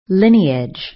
Watch out! You may see this word with another pronunciation and its meaning is then different.